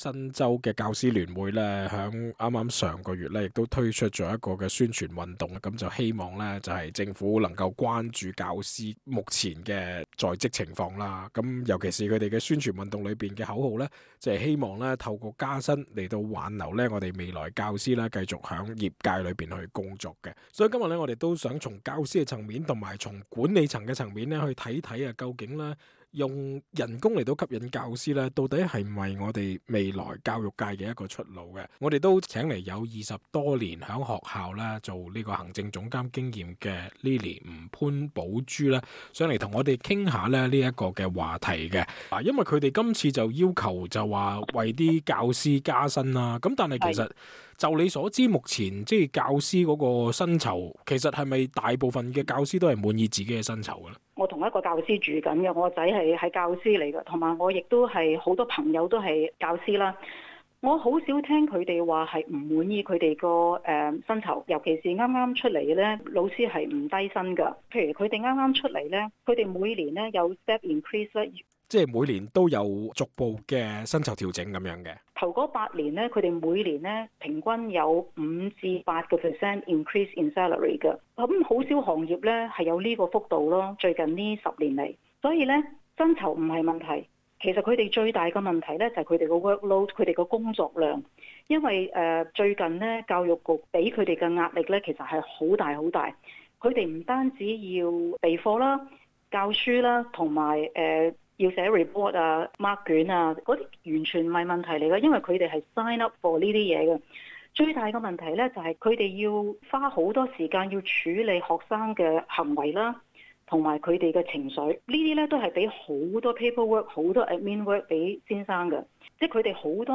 community_interview_pay_raise_for_teachers_podcast.mp3